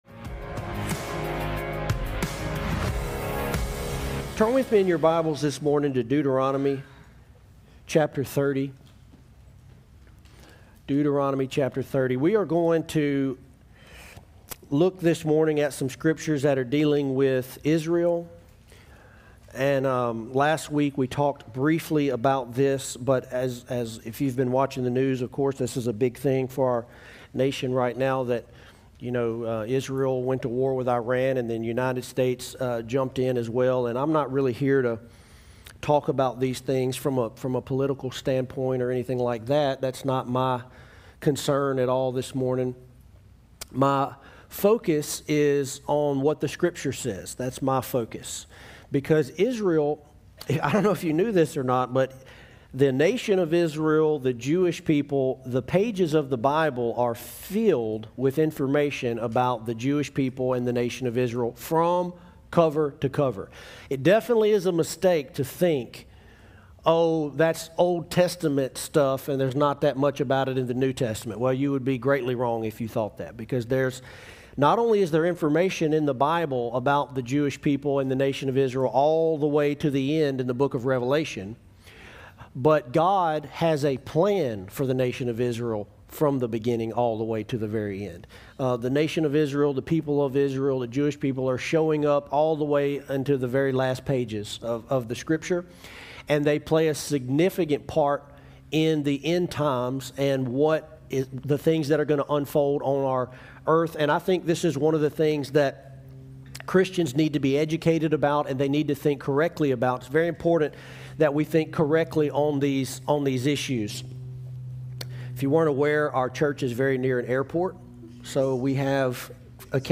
In a world that celebrates "freedom" but leaves us feeling trapped in toxic habits, Jesus invites us to a better way—a rebel way. In this final message from The Rebel Way series, we’re tearing down the illusion of freedom and digging into what it means to build a life that actually lasts.